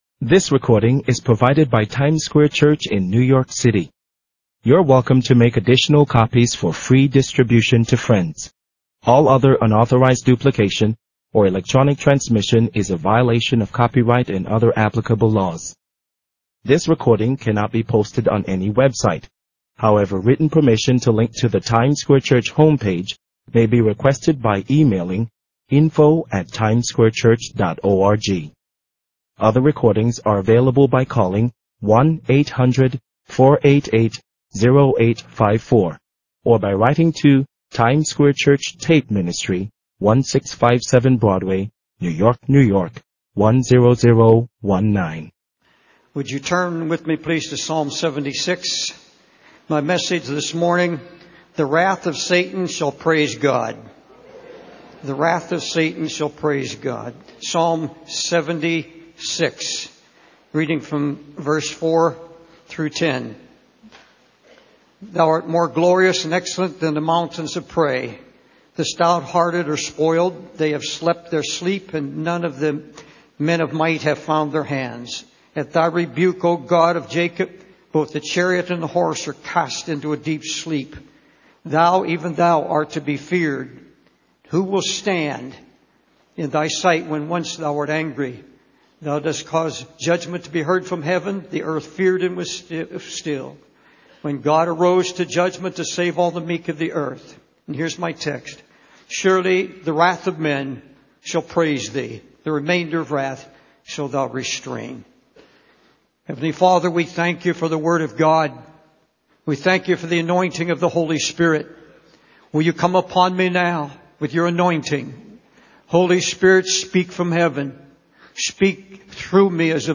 In this sermon, the preacher focuses on the story of Shadrach, Meshach, and Abednego from the book of Daniel.